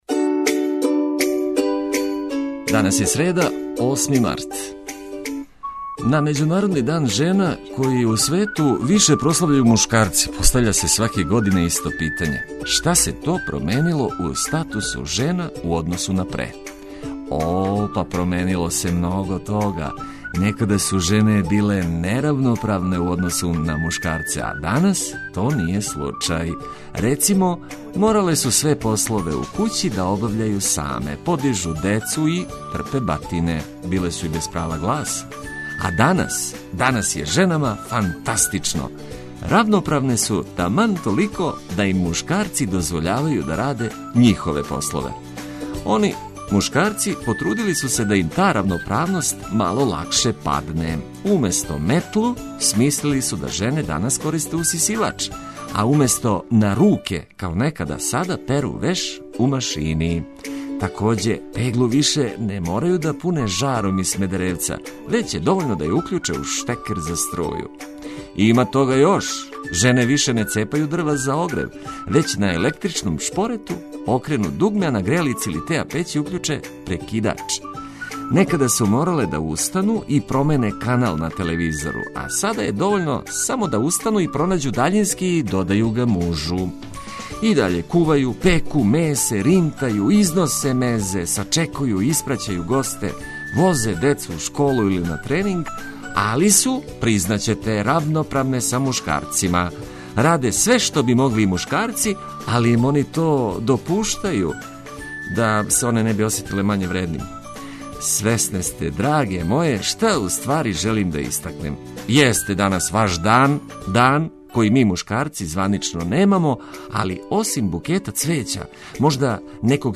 Најважније информације од користи за све који нас слушају уз брзи ритам наjбоље музике за лакше буђење.